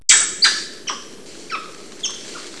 They also make a "barking" type noise. They are well known for their screeching type calls.
Spider Monkey
spidermonkey_sound.wav